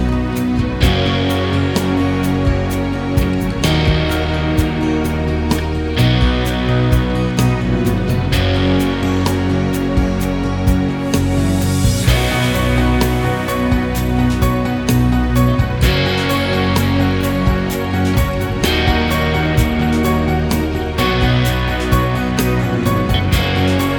Pop (2010s)